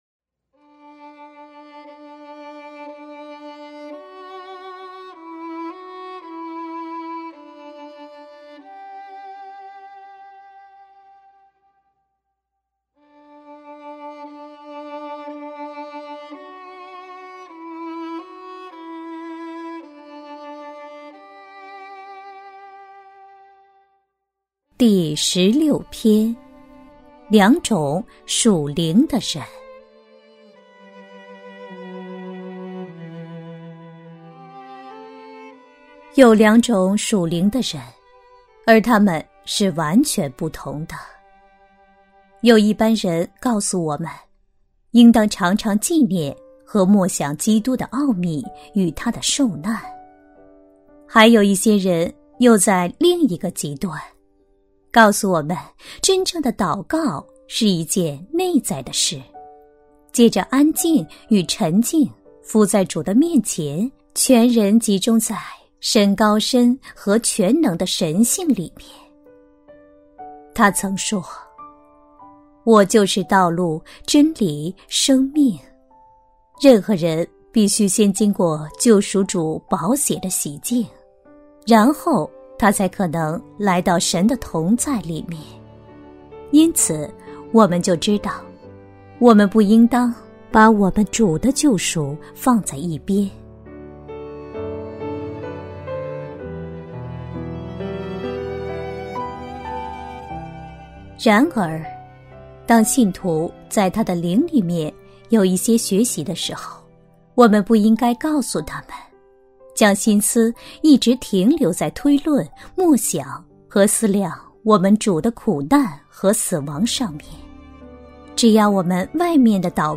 首页 > 有声书 | 灵性生活 | 灵程指引 > 灵程指引 第十六篇：两种属灵的人